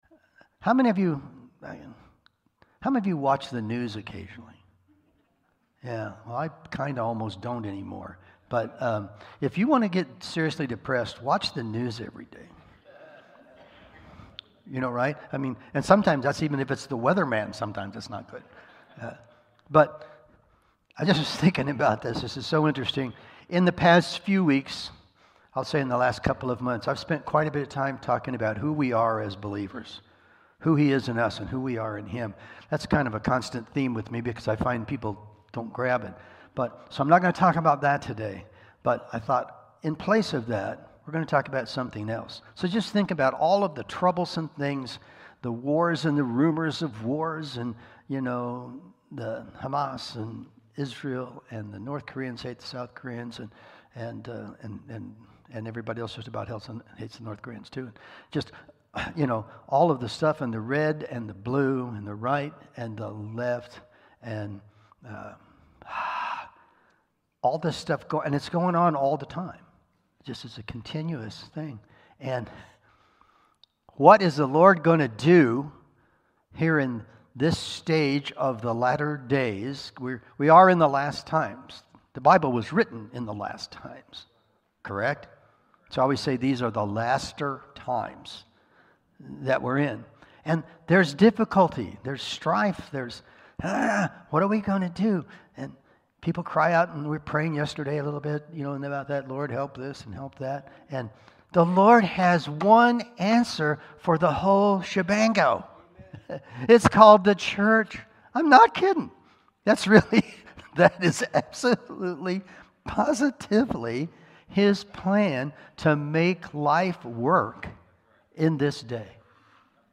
Ephesians 3:20-21 Service Type: Sunday Morning Sermon Download Files Notes